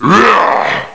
PSP/CTR: Also make weapon and zombie sounds 8bit